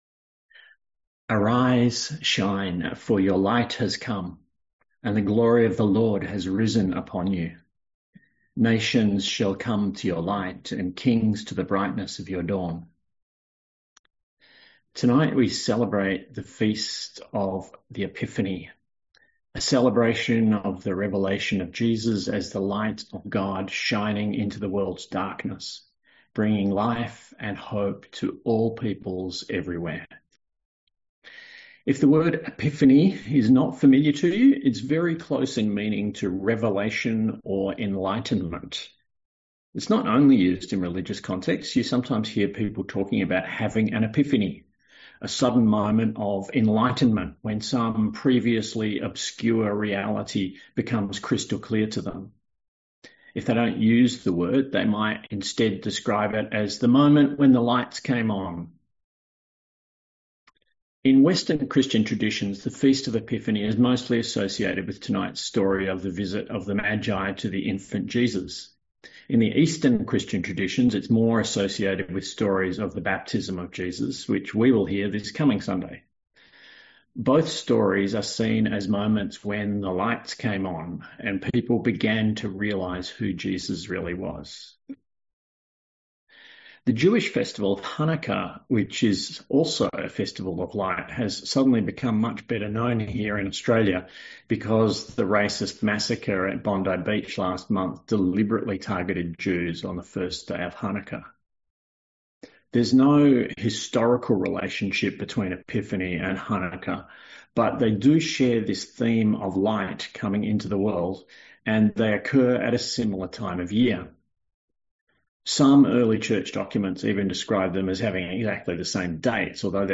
A sermon on Isaiah 60:1-6 & Matthew 2:1-12